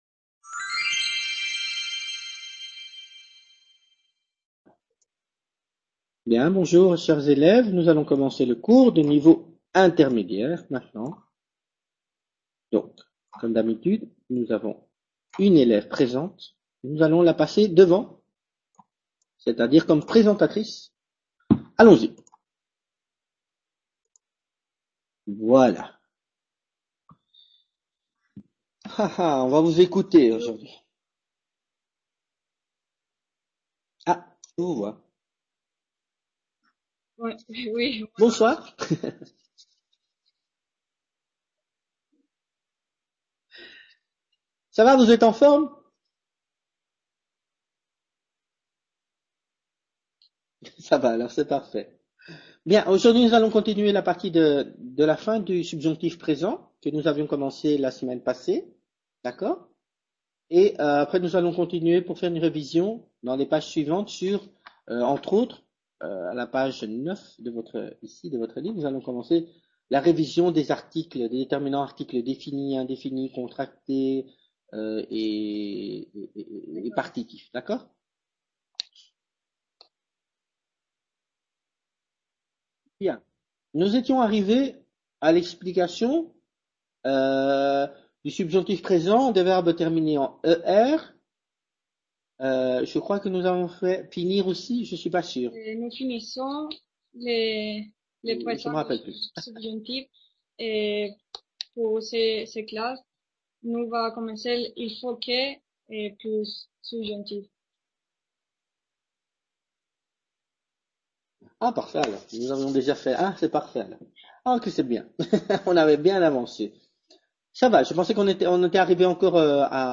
Clase de Francés Nivel Intermedio